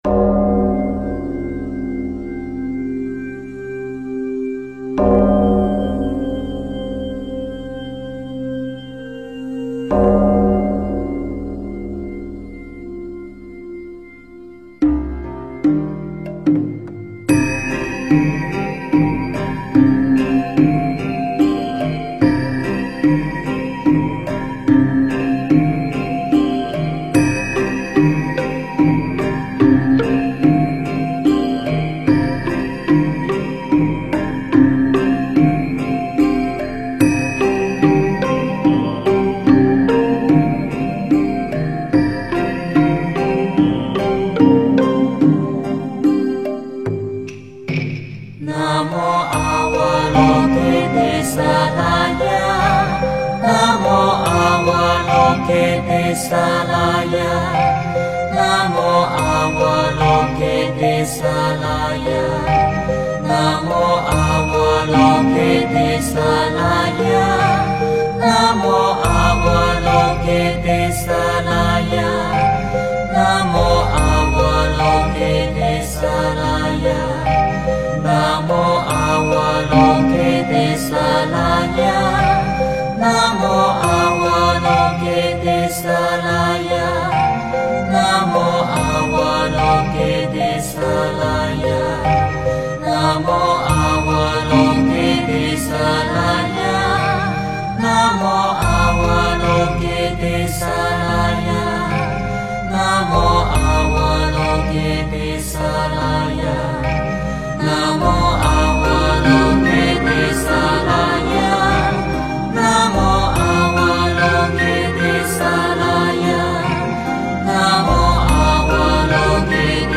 佛音 经忏 佛教音乐 返回列表 上一篇： 南无阿弥陀佛(弥陀颂